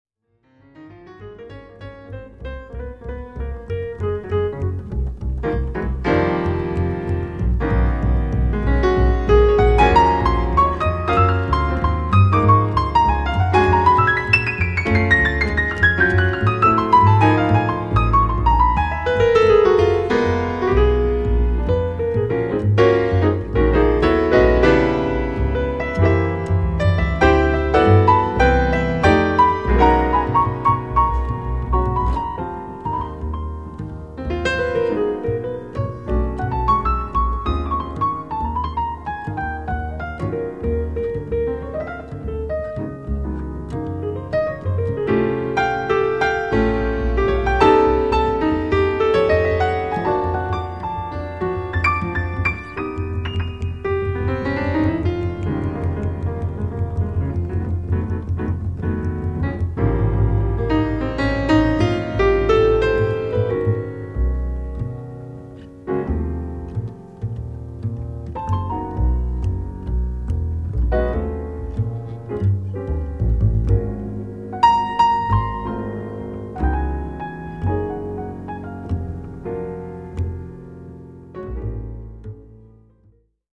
Recorded live at Keystone Korner, San Francisco, July, 1981